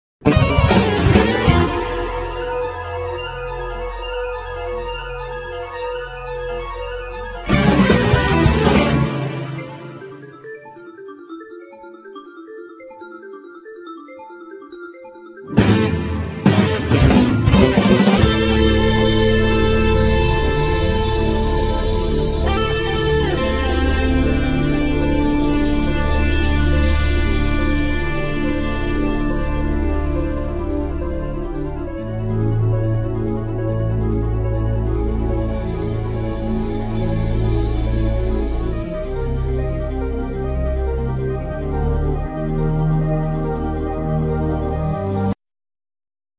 Guitars(Electric,Acoustic)
Keyboards
Bass
Drums